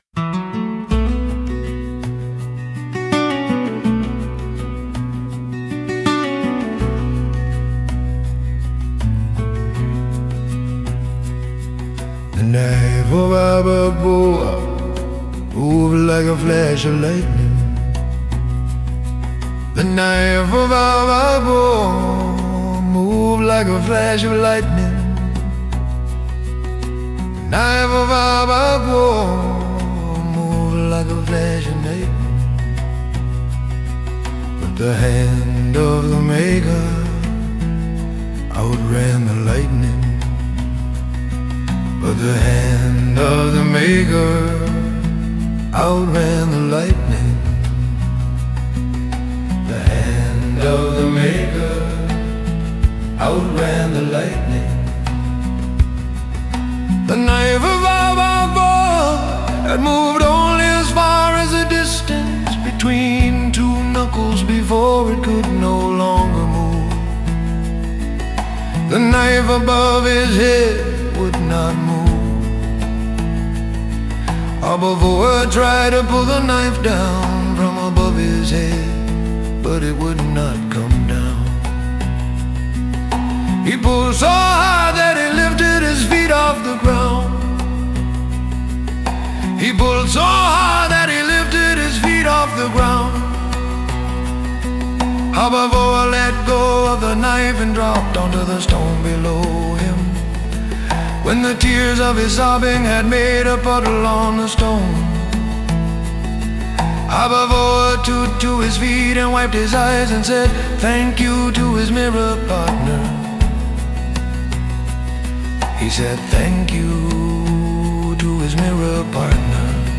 “Way Back in the Long Ago” is a campfire story told by a group of old men.